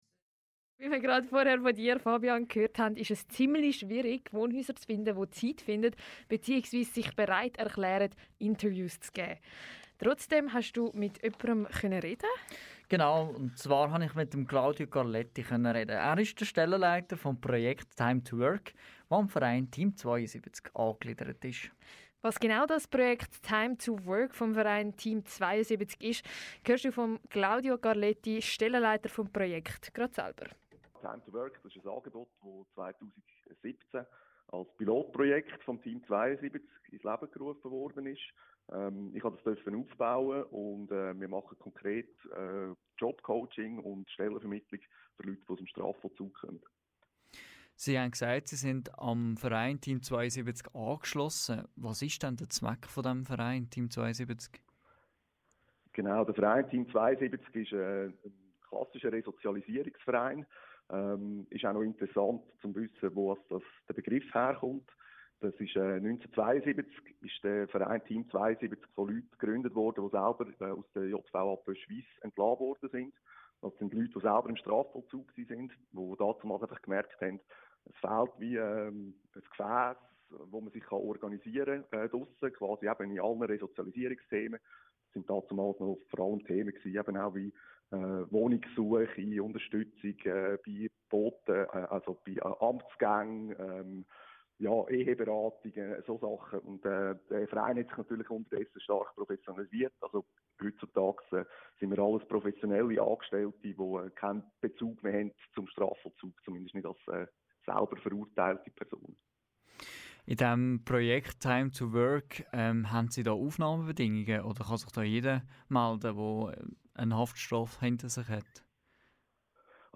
InterviewKanalK.mp3